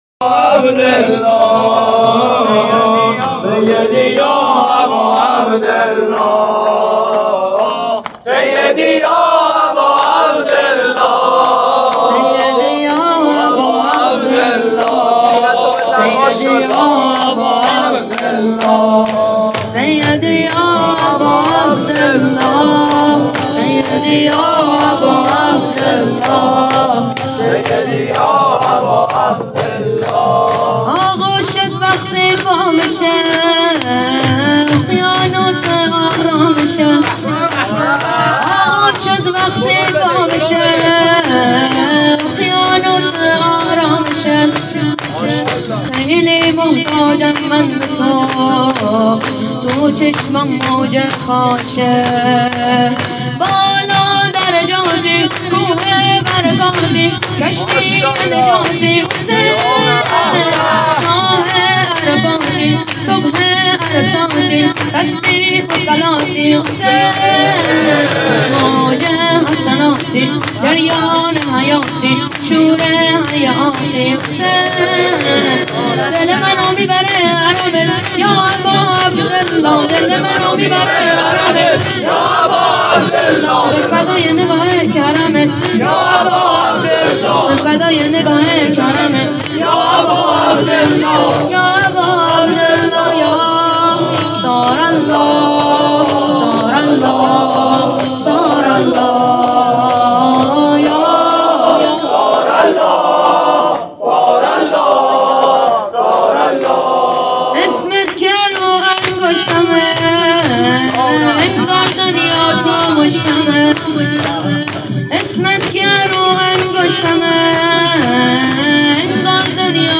نوحه شور آغوشت وقتی وابشه محبان الحسین
مراسم هفتگی هیئت محبان الحسین (ع)